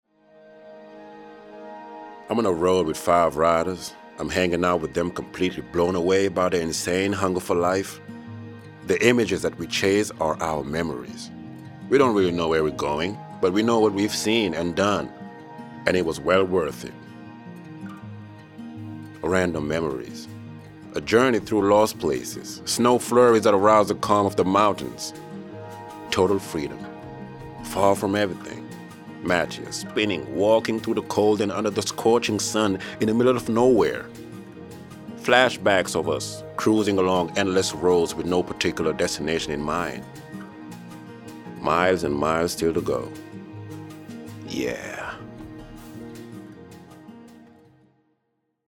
Voix-off
20 - 40 ans - Baryton-basse